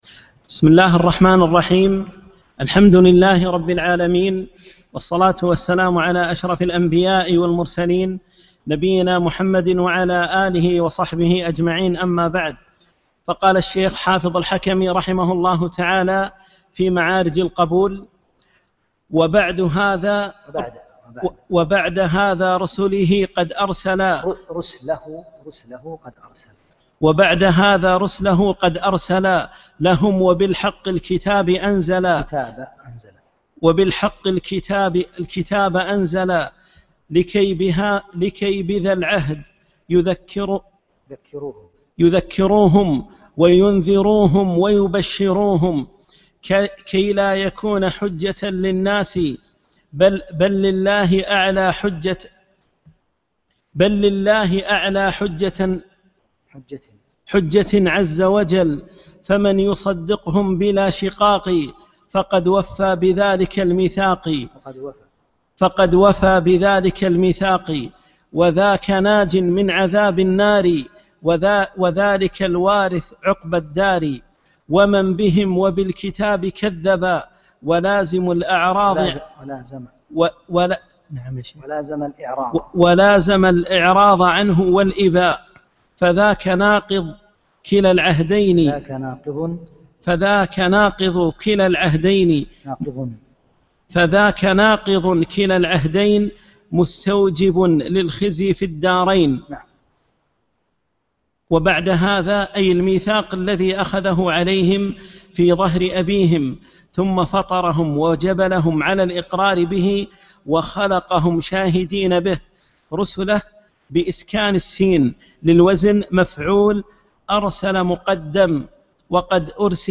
6 - الدرس السادس